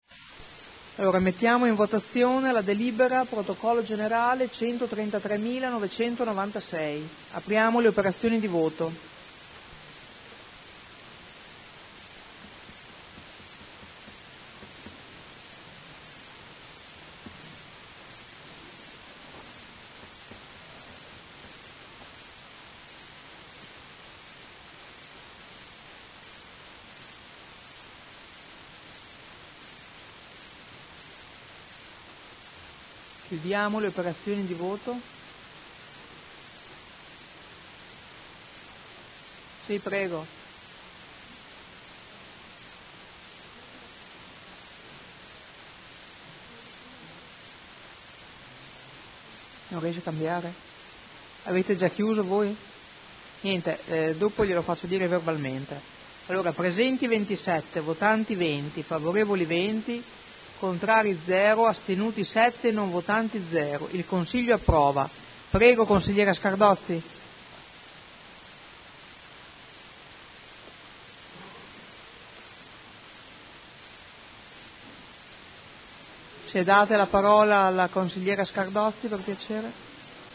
Presidente — Sito Audio Consiglio Comunale
Seduta del 22 ottobre. Proposta di deliberazione: Variante al Piano operativo comunale (POC) e al Regolamento urbanistico edilizio (RUE) – Controdeduzioni e approvazione ai sensi degli artt 33 e 34 della Legge regionale 20/2000 e s.m. Votazione